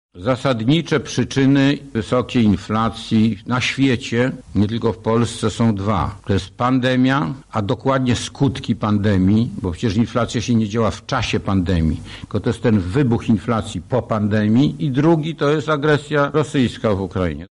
-tłumaczył na dzisiejszej (11.05) konferencji prasowej, Adam Glapiński prezes Narodowego Banku Polskiego.